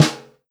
S_snare2_Ghost_1.wav